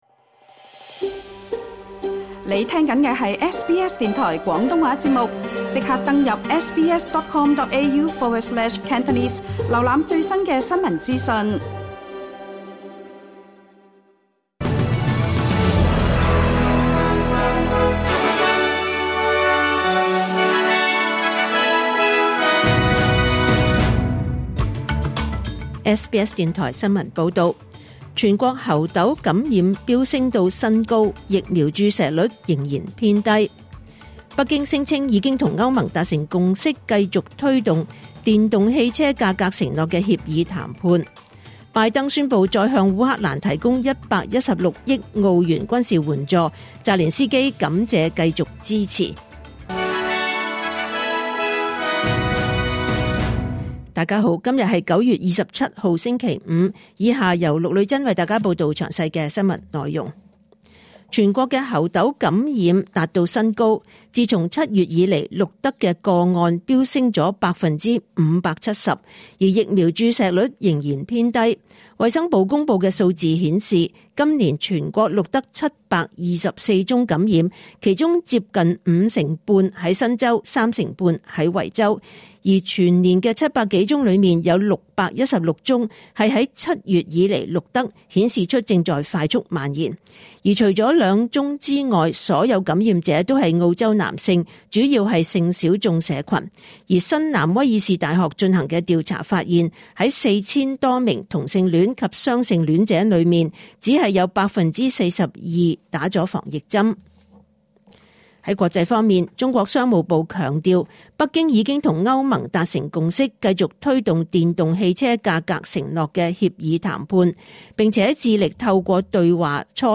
2024 年 9 月27 日 SBS 廣東話節目詳盡早晨新聞報道。